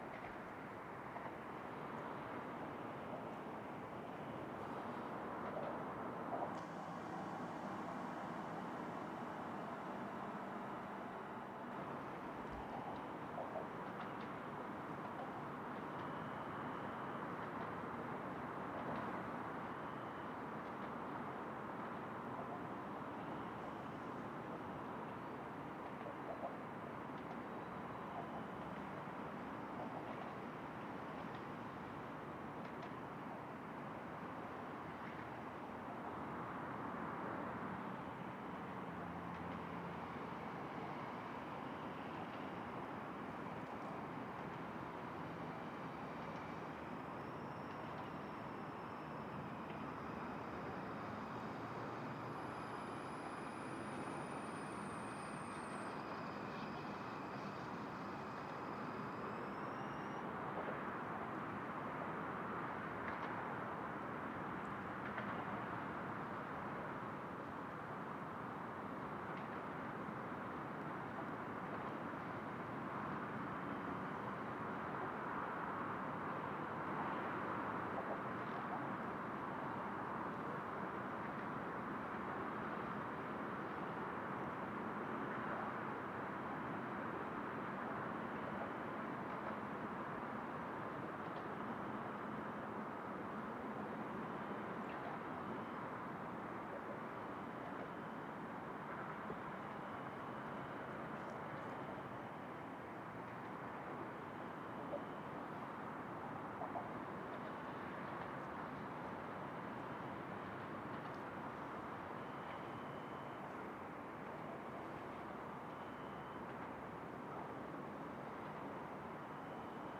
音乐猎手 城市之声 " azotea 遥远的交通 2
Tag: 遥远 气氛 环境 交通 现场记录 城市